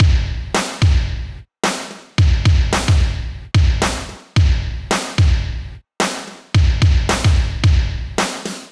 • 110 Bpm Drum Loop B Key.wav
Free drum loop sample - kick tuned to the B note. Loudest frequency: 1145Hz
110-bpm-drum-loop-b-key-alY.wav